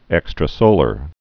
(ĕkstrə-sōlər)